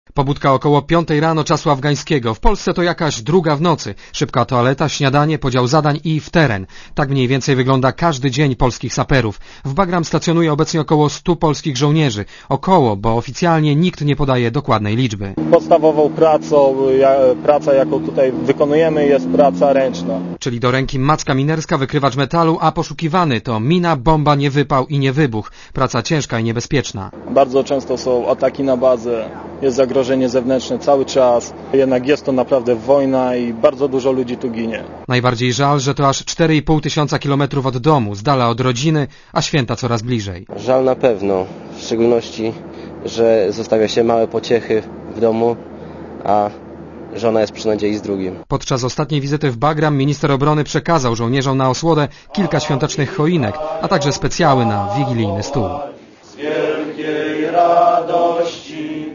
Był u nich report Radia ZET.
Źródło zdjęć: © RadioZet 9 grudnia 2004, 16:44 ZAPISZ UDOSTĘPNIJ SKOMENTUJ Posłuchaj jego relacji